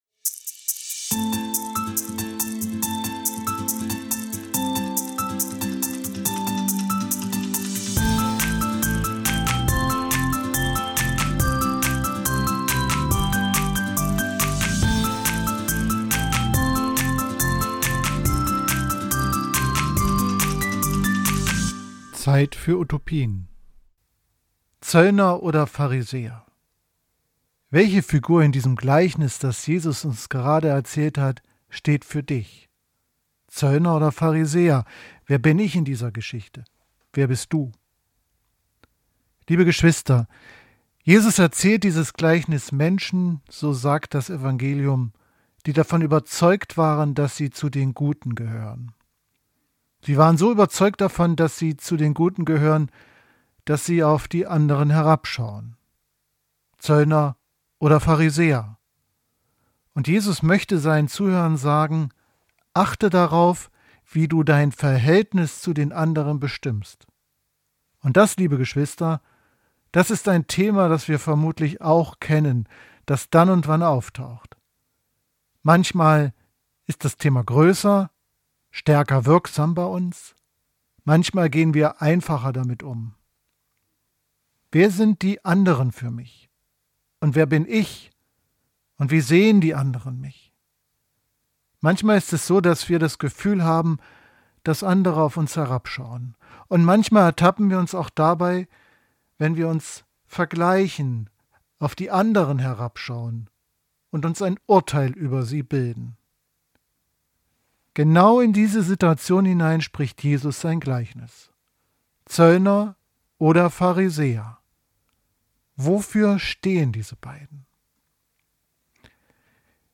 Predigt am30. Sonntag im Jahreskreis in der Kathedrale St. Sebastian Magdeburg (26. Oktober 2025)